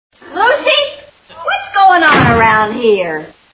I Love Lucy TV Show Sound Bites